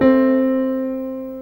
F_FA.ogg